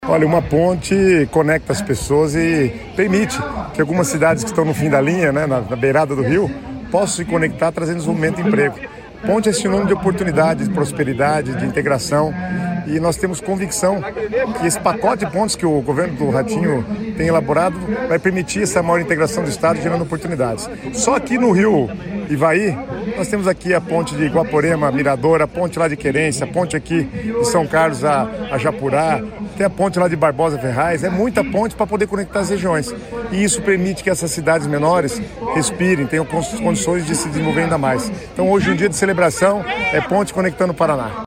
Sonora do secretário das Cidades, Guto Silva, sobre obra da ponte entre Japurá e São Carlos do Ivaí